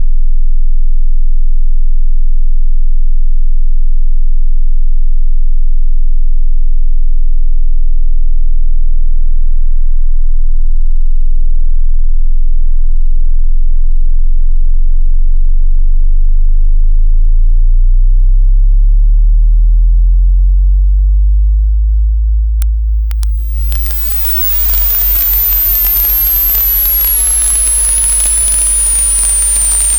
Це соніфікація структурної ентропії. 75 секунд реальності.
Я почав з 20 Гц серцебиття. Стабільний стан. Чистий синус. Потім я ввів «Коефіцієнт дрижання». Зі зростанням γ демпфування відмовляє. Система заїкається.
Потім настають розриви. Я моделював відмову зв’язку на 110 кГц як високочастотний розрив. Клацання в темряві. Потім «закипання» — втрата гістерезису. Гудіння на 50 Гц і зростаючий рівень шуму.
Цей високочастотний крик наприкінці? Це зсув під кутом 45 градусів.